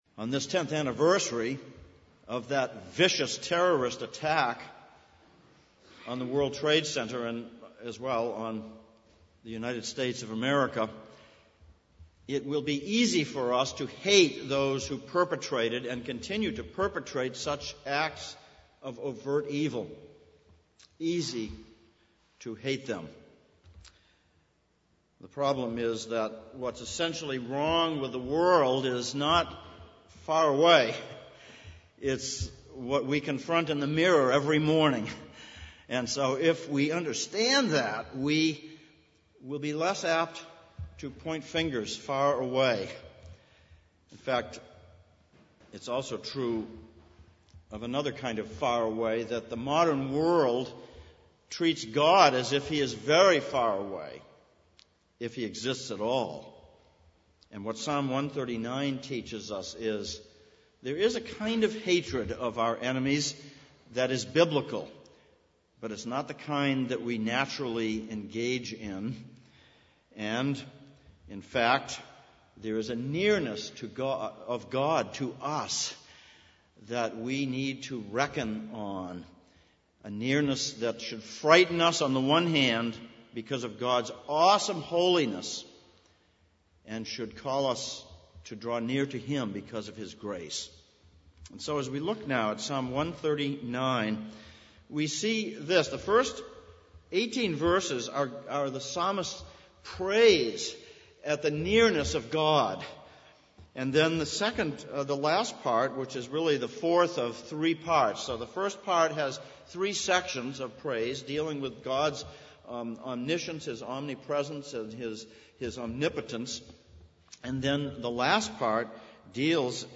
Hebrews 4:1-Psalm 4:16 Service Type: Sunday Morning Sermon